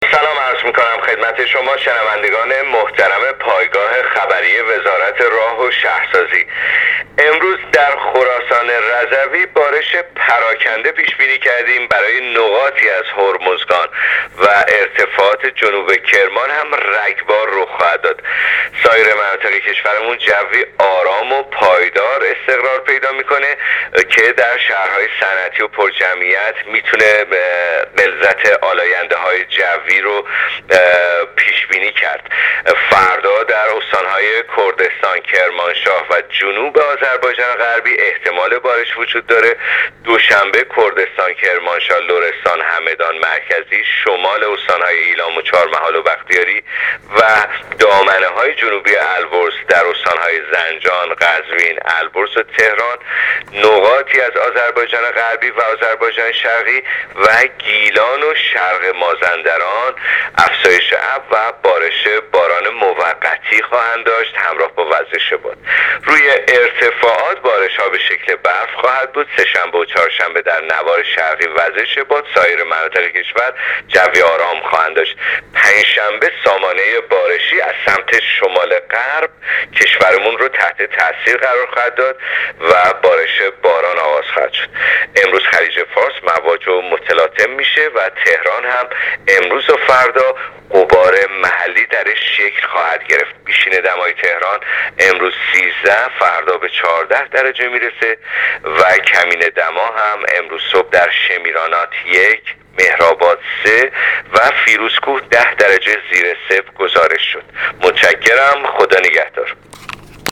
کارشناس سازمان هواشناسی کشور در گفت‌وگو با رادیو اینترنتی وزارت راه‌وشهرسازی، آخرین وضعیت آب‌و‌هوای کشور را تشریح کرد.
گزارش رادیو اینترنتی از آخرین وضعیت آب‌‌و‌‌‌هوای سیزدهم آذر؛